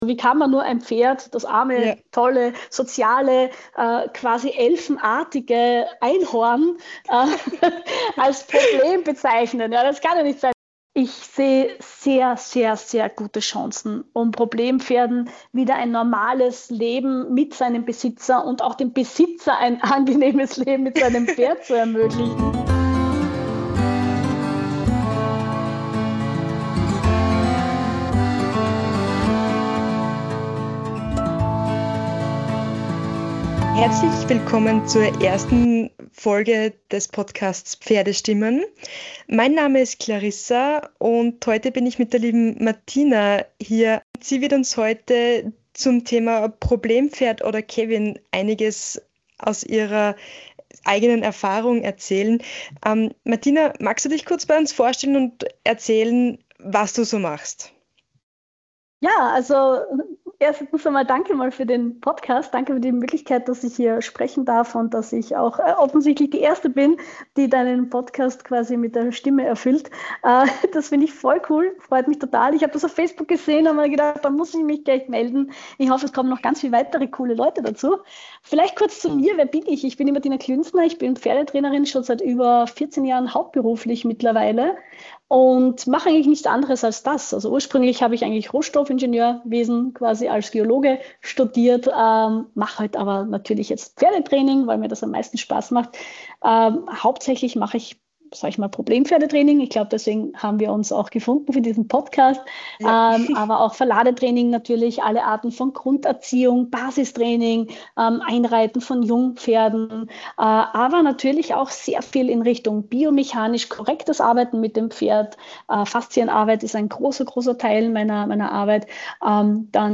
Hier kommen nicht nur Reiterinnen und Reiter zu Wort, sondern auch Trainer, Pferdebesitzer und Expertinnen aus allen Bereichen des Reitsports.